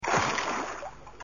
Plask
Plask.mp3